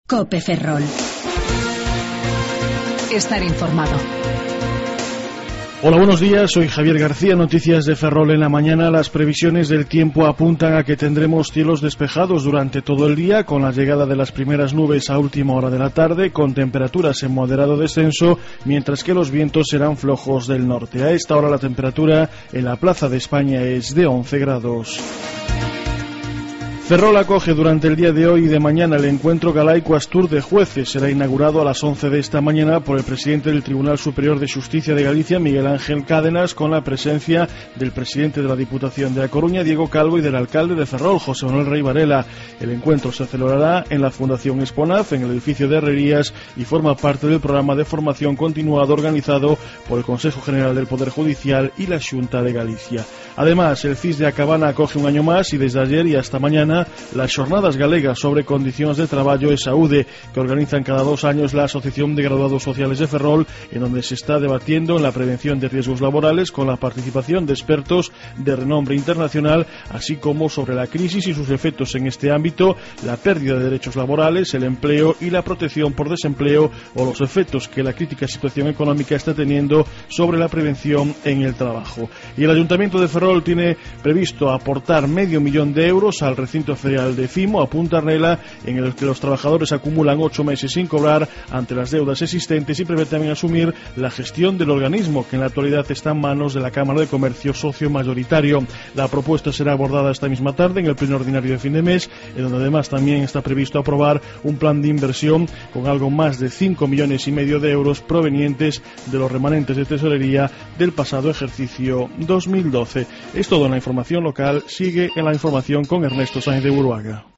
07:58 Informativo La Mañana